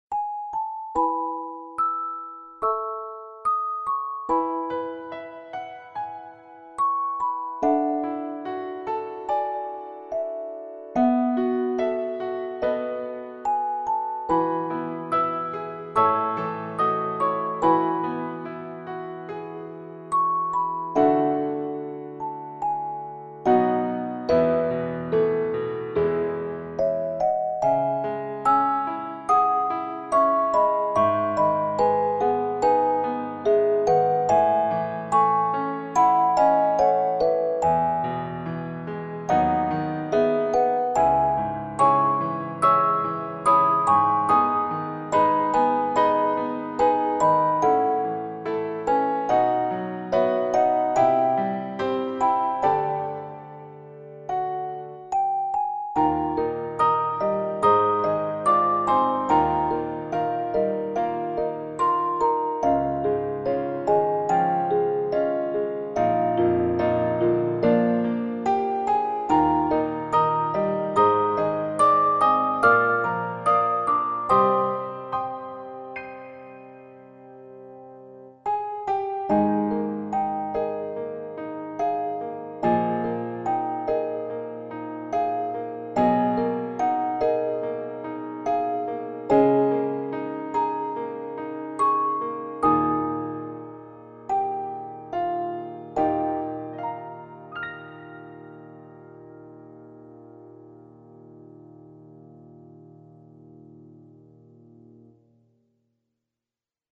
切ない曲。